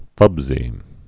(fŭbzē)